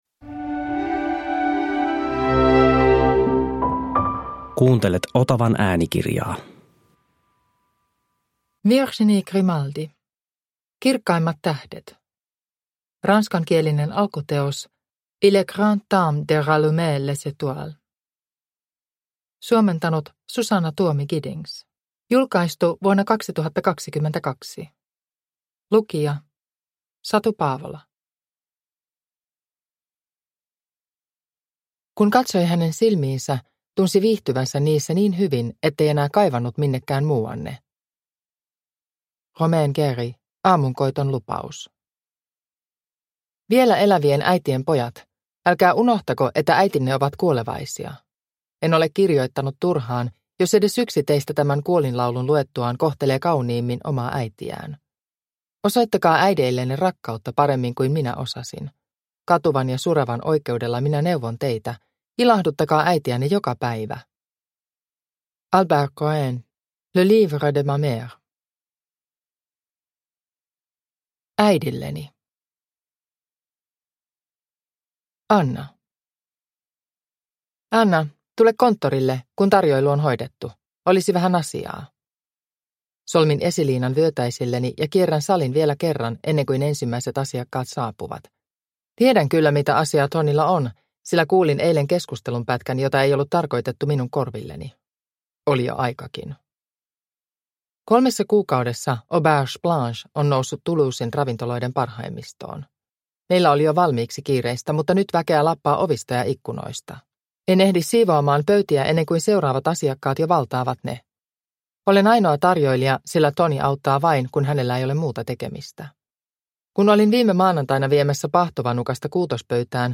Kirkkaimmat tähdet – Ljudbok – Laddas ner